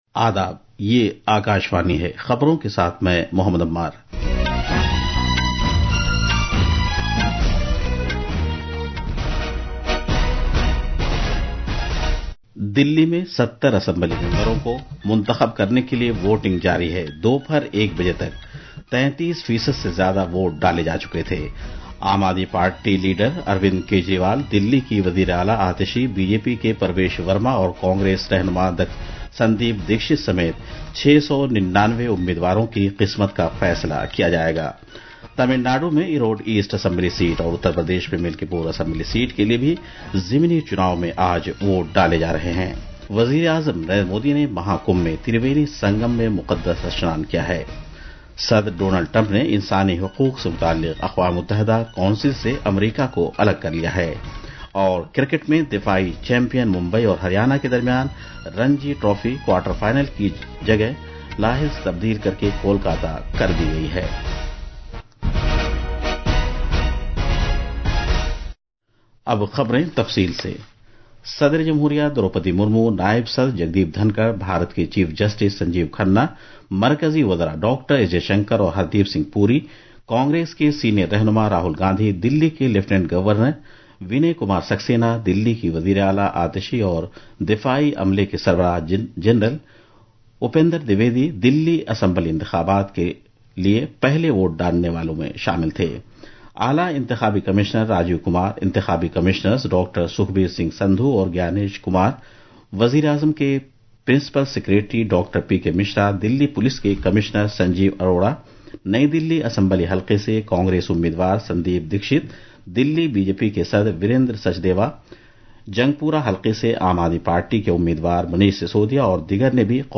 قومی بلیٹنز